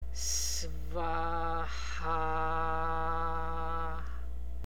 Може да замените НАМА със СВАХА ٠ [С-ВАА-ХХА], когато работите за постигане на духовни цели